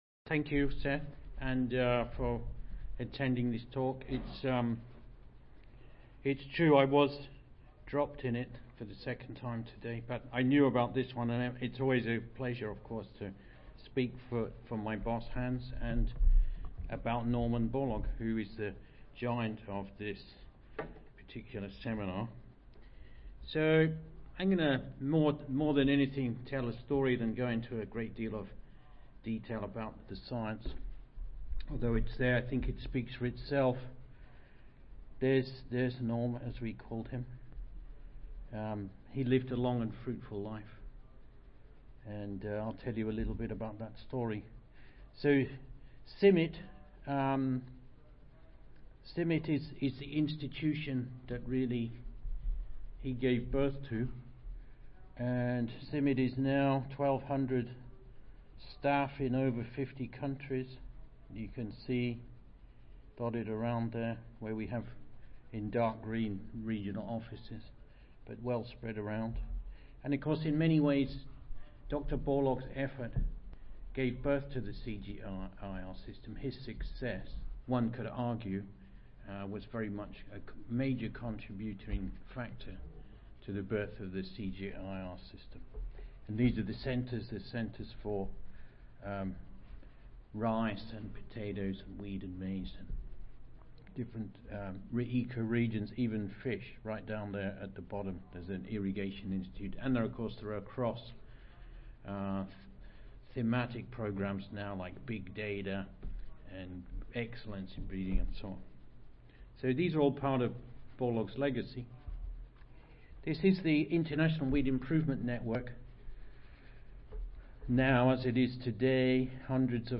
See more from this Division: C01 Crop Breeding and Genetics See more from this Session: Symposium--Giants of Agricultural Progress and Impacts from Public Agricultural Research